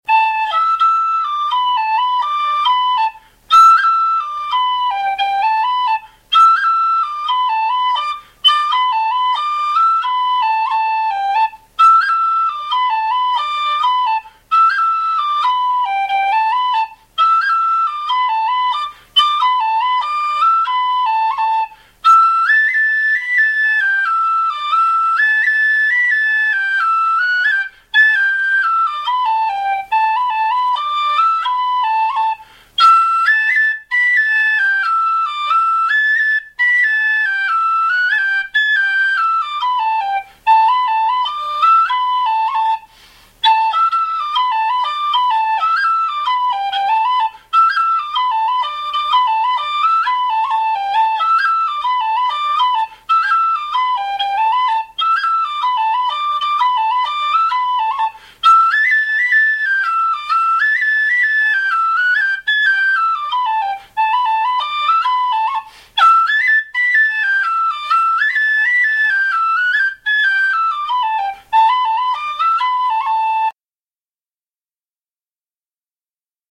Padraig O'Keeffe's slide Am pdf 18KB txt